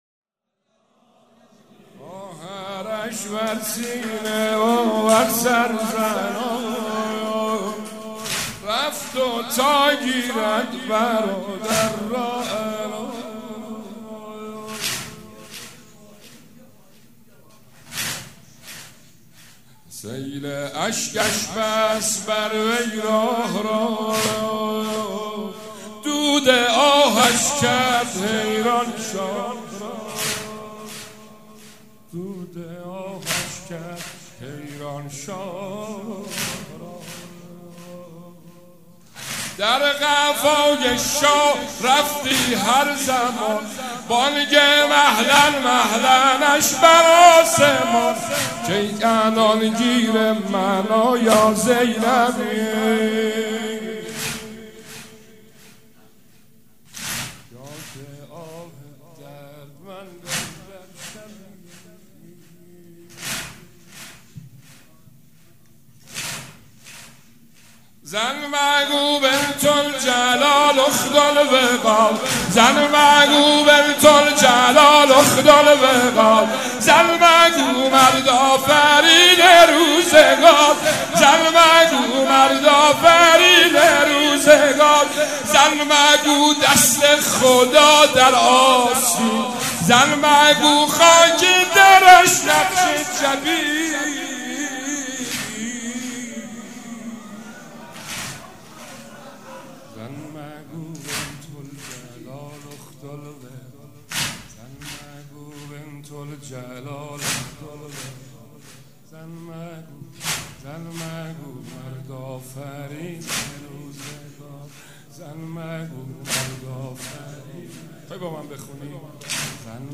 مراسم شهادت حضرت زینب کبری(سلام الله عليها)
مداح